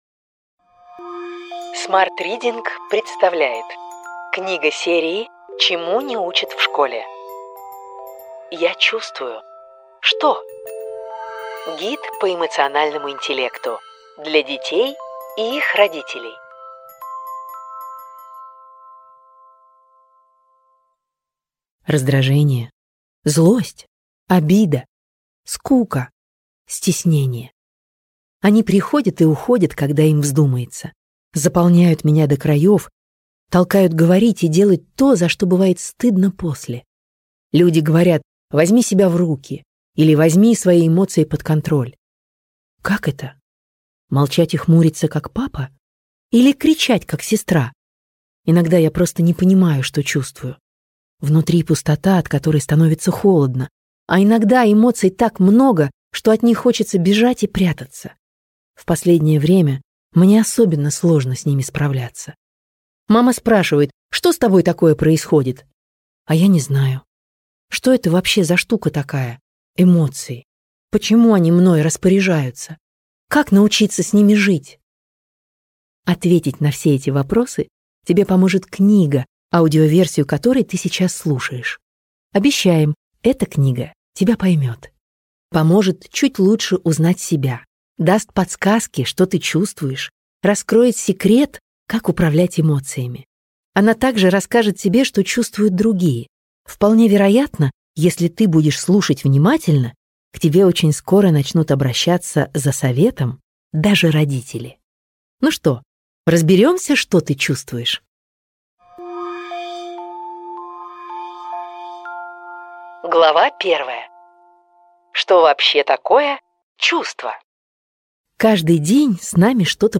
Аудиокнига Я чувствую… Что? Книга-гид по эмоциональному интеллекту для детей и подростков.